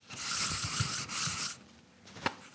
household
Toilet Paper Noise